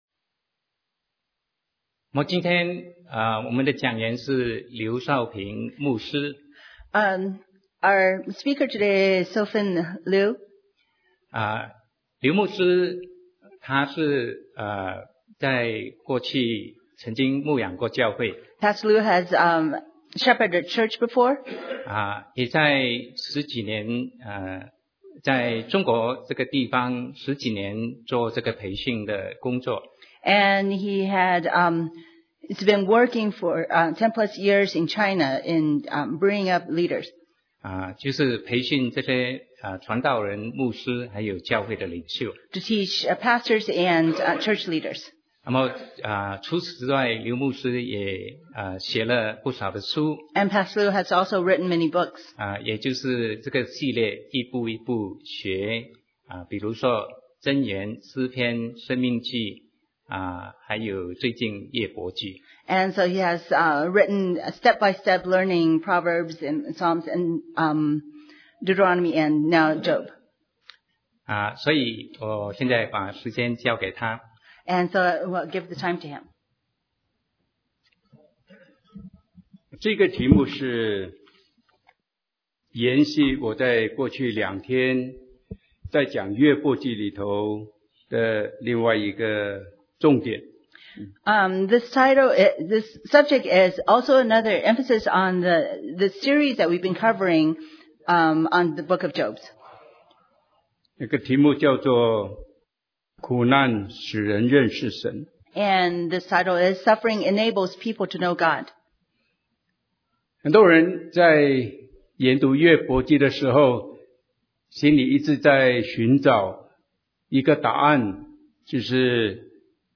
Sermon 2016-11-06 Suffering Enables People to Know God – Albuquerque Chinese Baptist Church ✟ 阿布奎基華人浸信會